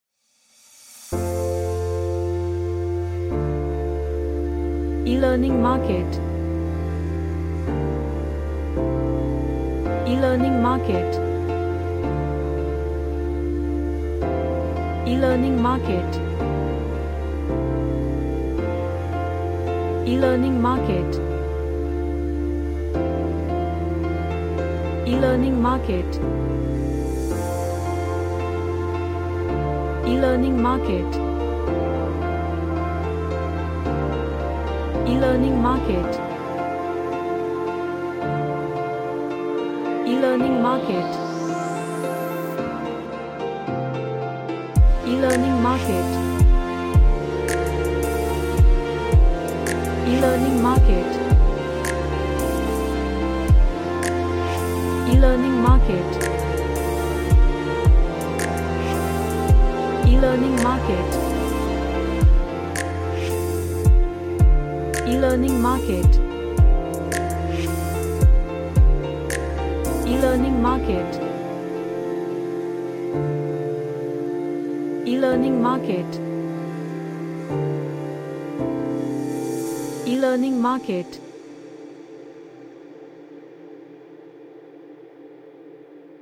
Ambient Music For relaxation
Emotional